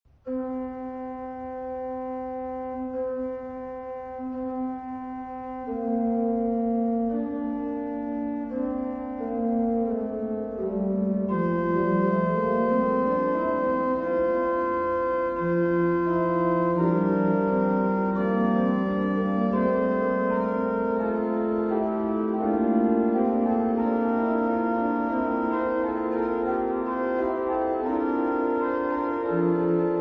Personaggi e interpreti: organo ; Leonhardt, Gustav